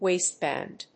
アクセント・音節wáist・bànd